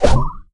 throw_bawl_01.ogg